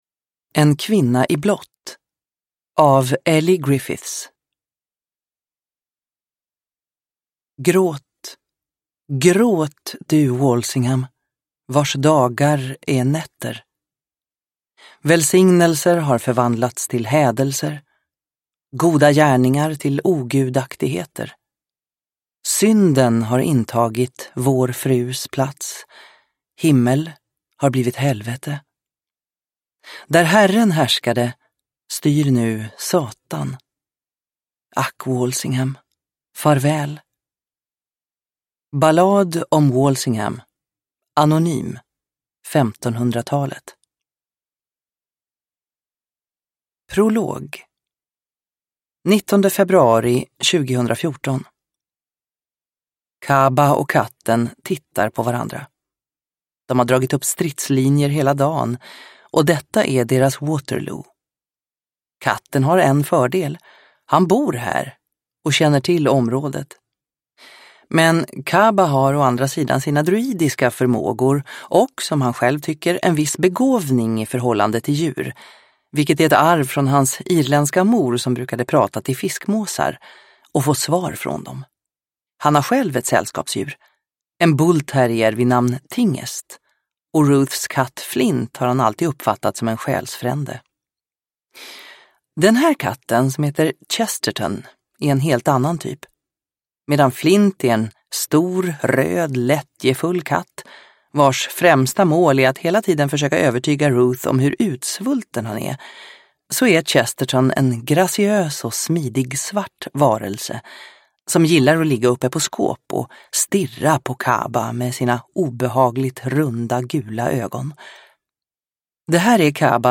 En kvinna i blått – Ljudbok – Laddas ner